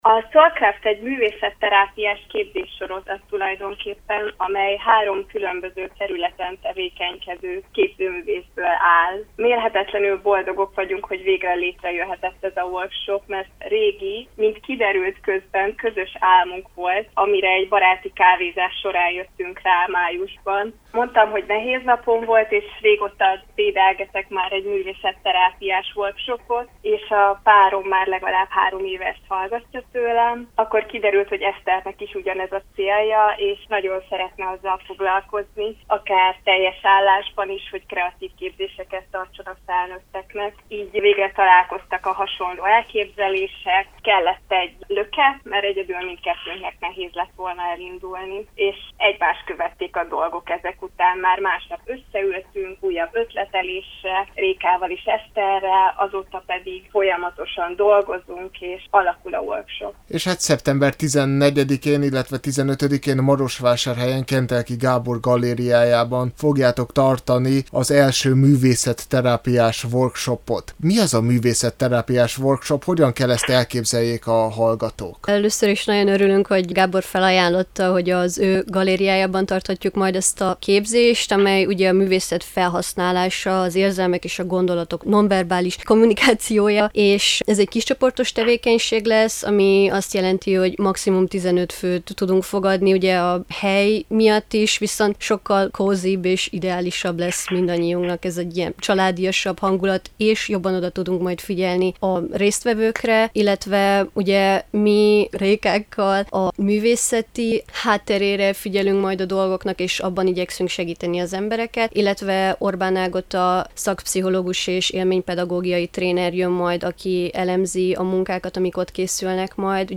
A SoulCraft csapata arra törekszik, hogy személyes útmutatást nyújtsanak és megosszák saját tapasztalataikat az önkifejezés művészetéről. Magáról a SoulCraftről és a művészetterápiás workshopról beszélgetett